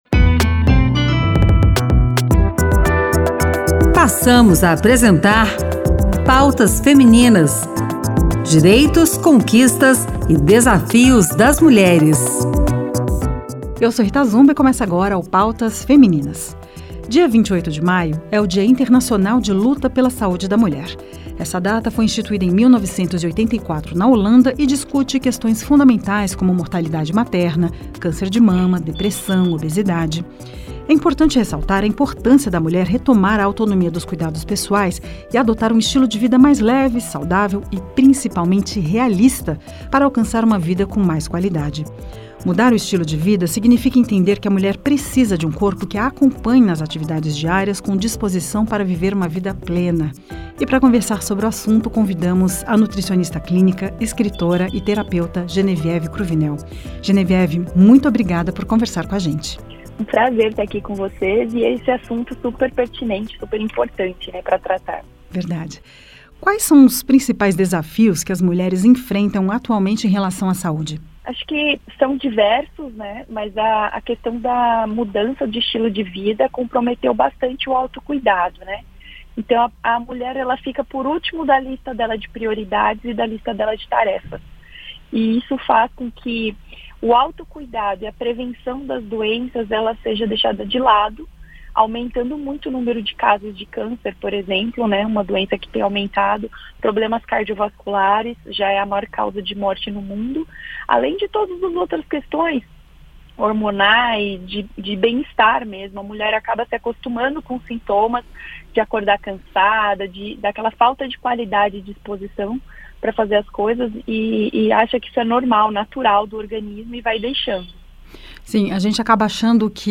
Essa data foi instituída em 1984 na Holanda e discute questões fundamentais como mortalidade materna, câncer de mama, depressão, obesidade...No programa de hoje conversamos com a nutricionista clínica, escritora e terapeuta